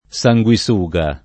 vai all'elenco alfabetico delle voci ingrandisci il carattere 100% rimpicciolisci il carattere stampa invia tramite posta elettronica codividi su Facebook sanguisuga [ S a jgU i S2g a ] (settentr. ant. sansuga [ S an S2g a ]) s. f.